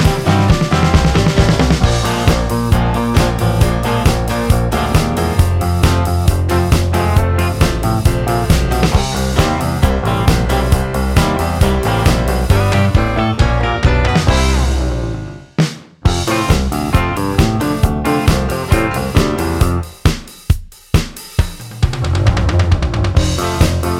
Duet Version T.V. Themes 2:23 Buy £1.50